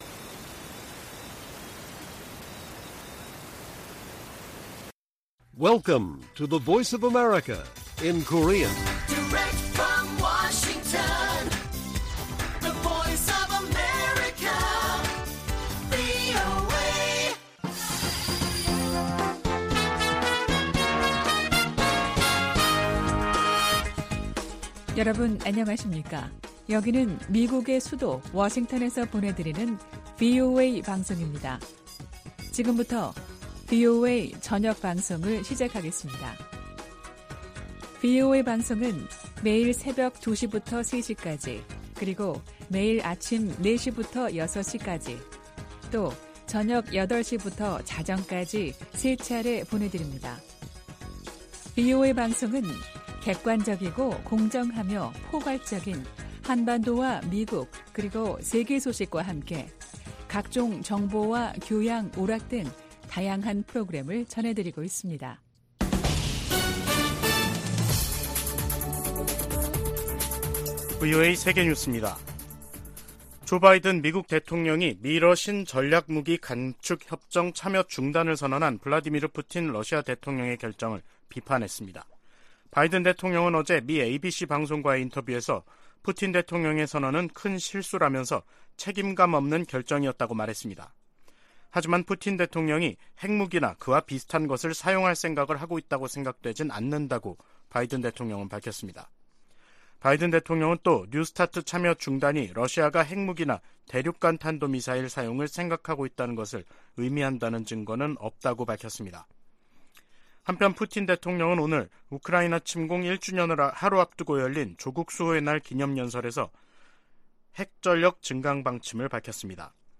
VOA 한국어 간판 뉴스 프로그램 '뉴스 투데이', 2023년 2월 23일 1부 방송입니다. 미 국무부가 북한의 최근 ICBM 발사를 거듭 규탄한 가운데 국방부는 북한의 계속되는 탄도미사일 시험 발사가 역내 불안정을 야기한다고 비판했습니다.